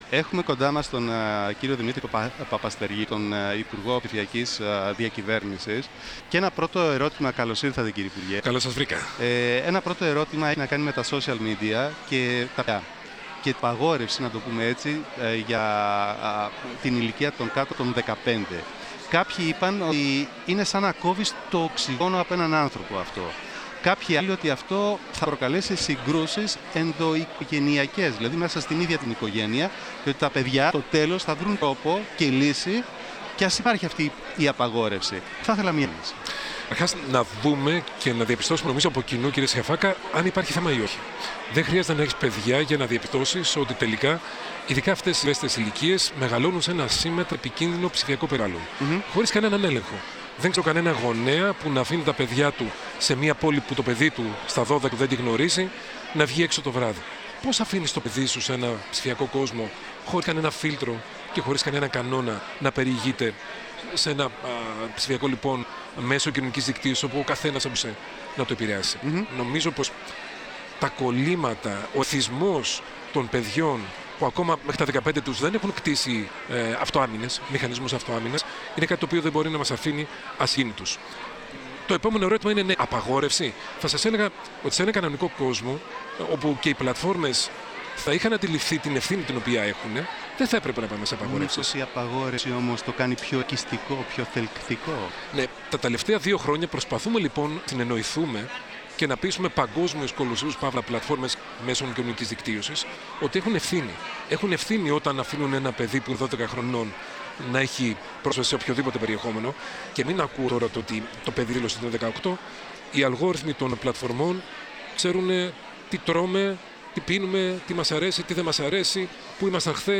από το Οικονομικό Φόρουμ των Δελφών, για το ζήτημα που αφορά την απαγόρευση των social media για παιδιά κάτω των 15 ετών.